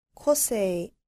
• こせい
• kosei